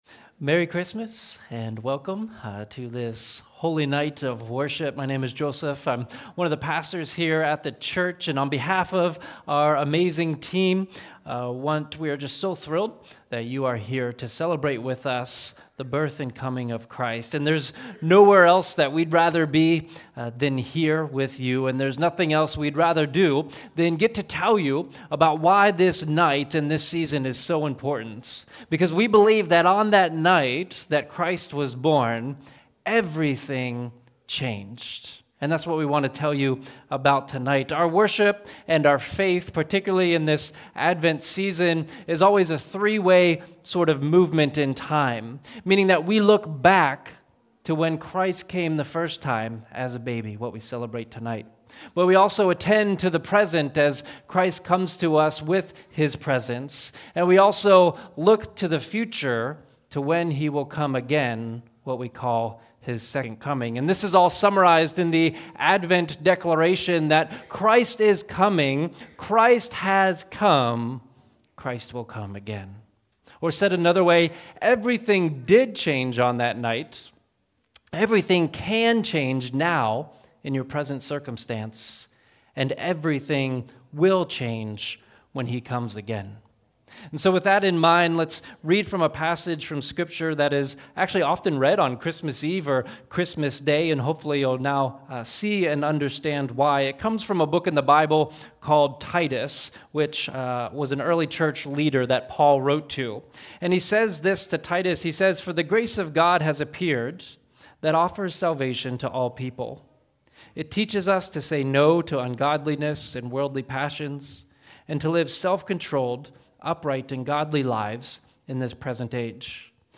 Christmas Eve Message 2024